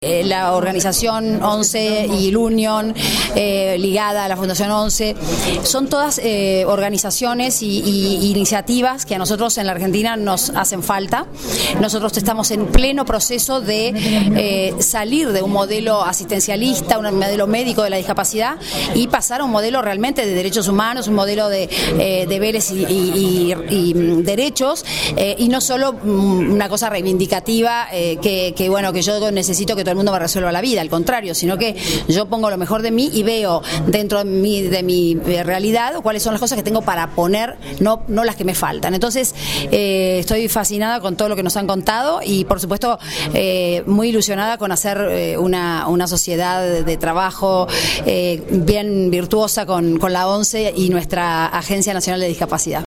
señaló tras conocerlo Michetti formato MP3 audio(1,01 MB) en relación con la política que pretende impulsar el Gobierno de Mauricio Macri en este terreno.